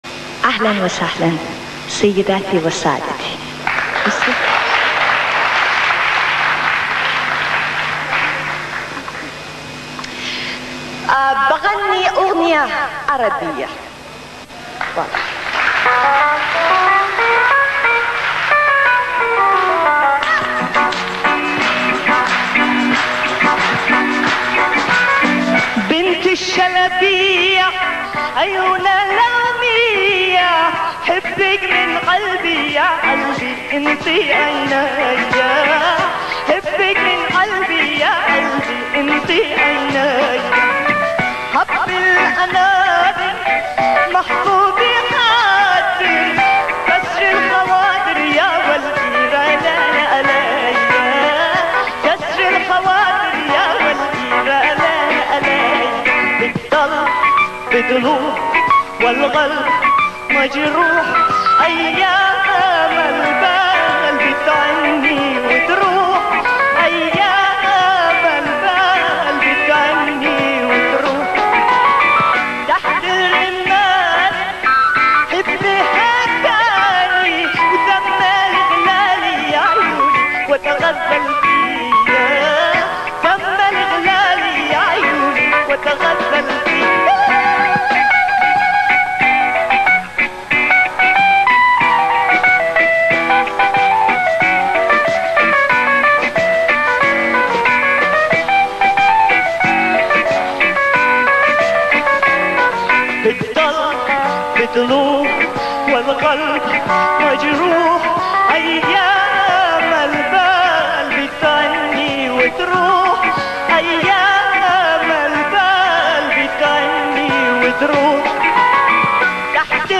ترانه فولکلور عربی